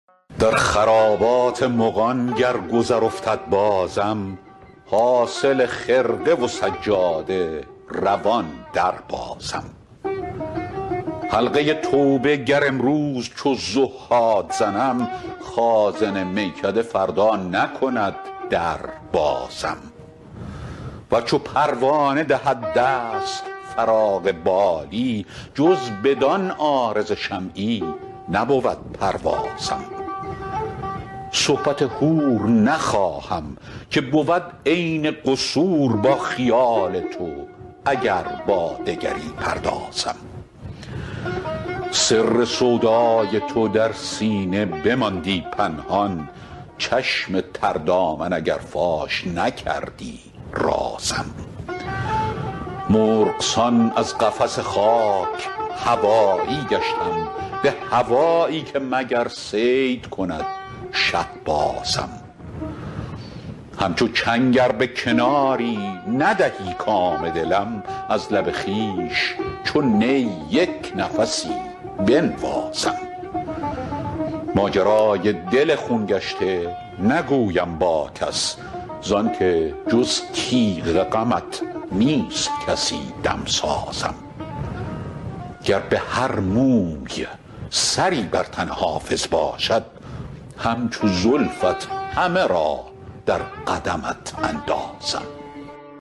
حافظ غزلیات غزل شمارهٔ ۳۳۵ به خوانش فریدون فرح‌اندوز